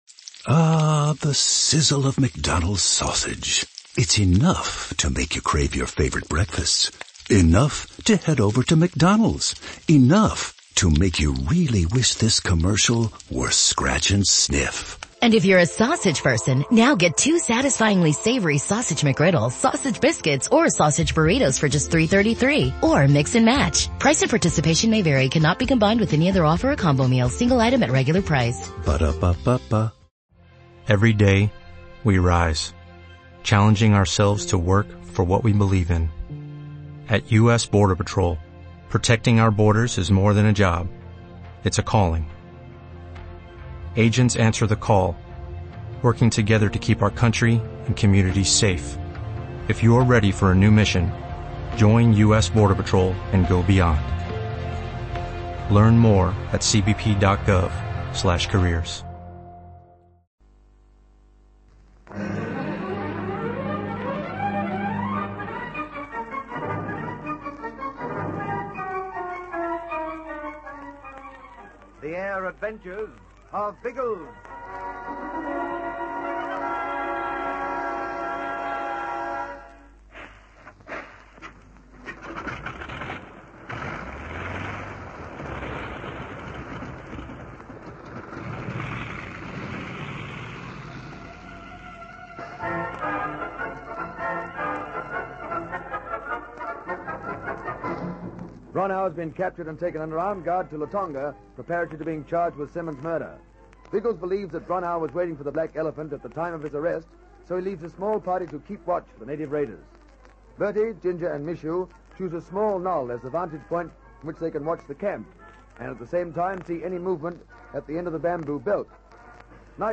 The Air Adventures of Biggles was a popular radio show that ran for almost a decade in Australia, from 1945 to 1954.
Biggles and his trusty companions, Ginger Hebblethwaite and Algy Lacey, soared through the skies in a variety of aircraft, from biplanes to jet fighters, taking on villains, rescuing damsels in distress, and generally having a whale of a time. The show was known for its exciting sound